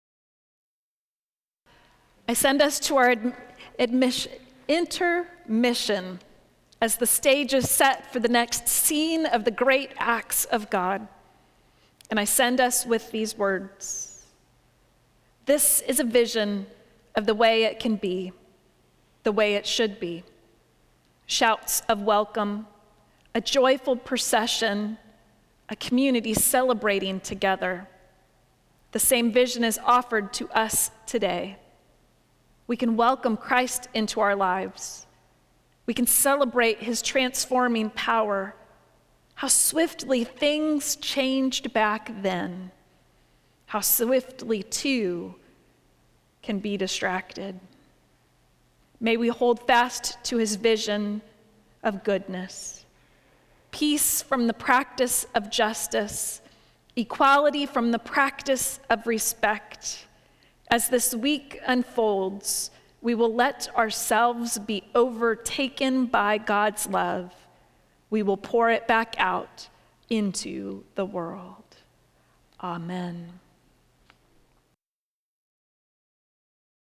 Service of Worship
Benediction